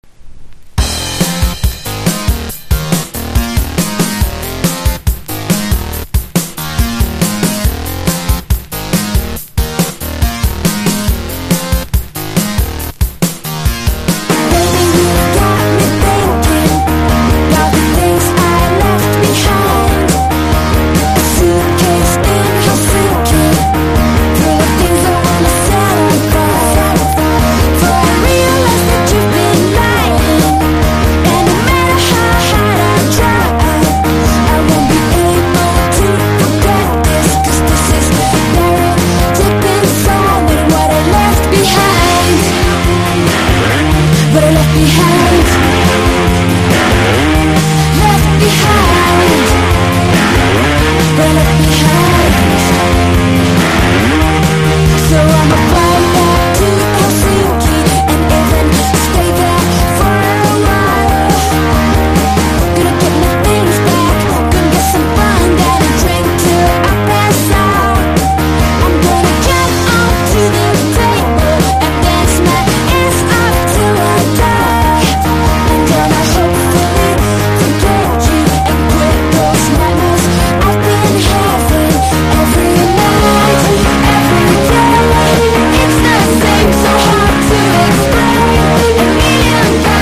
INDIE DANCE
ELECTRO POP